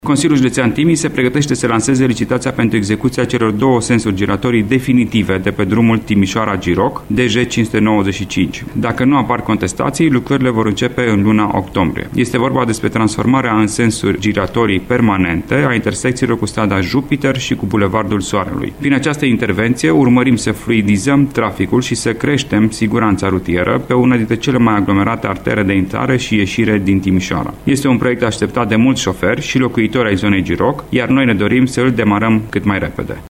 Partea de proiectare este încheiată, iar Consiliul Judetean Timiș urmează să lanseze licitația, spune vicepresedintele Alexandru Iovescu.